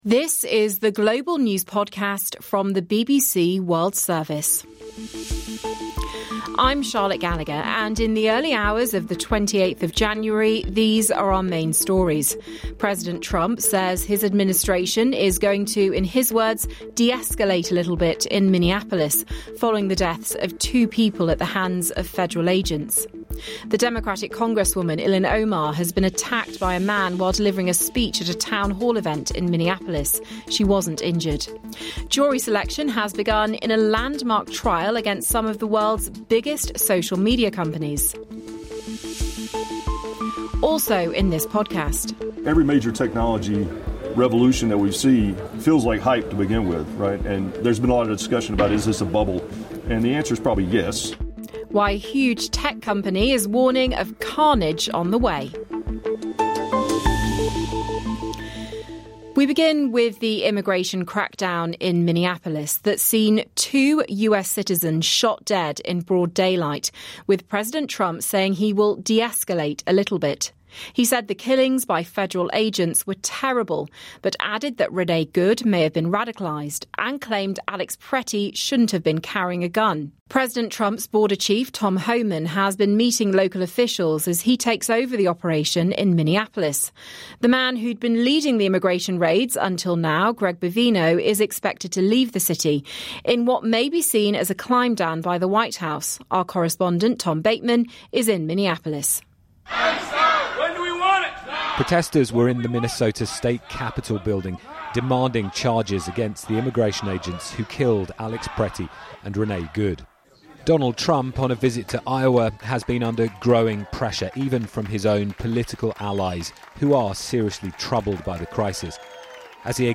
BBC全球新闻